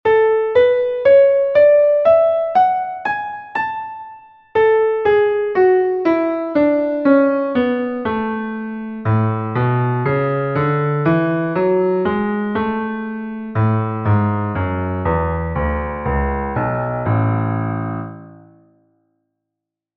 A-Dur
Die Tonart A-Dur, ihre Tonleiter aufsteigend und absteigend, notiert im Violinschlüssel und Bassschlüssel.
A-Dur.mp3